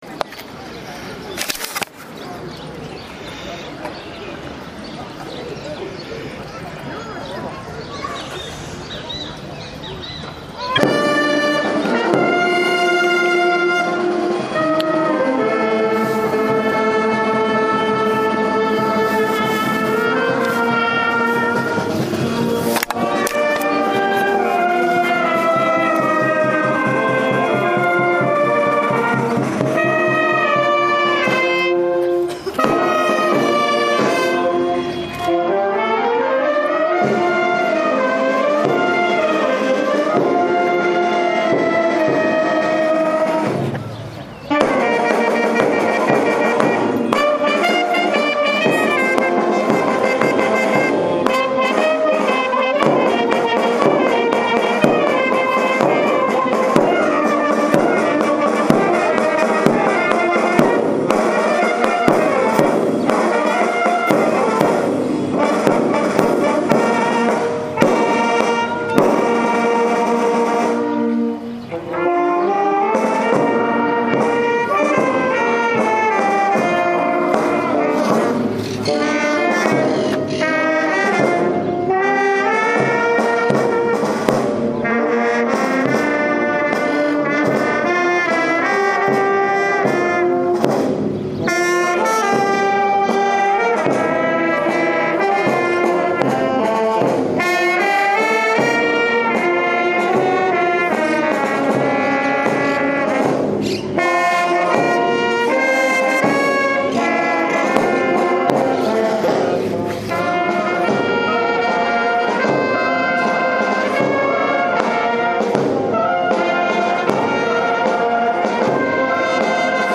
153º ANIVERSARIO DE RAMALLO. ACTO CENTRAL EN LA PLAZA PRINCIPAL JOSÉ MARÍA BUSTOS.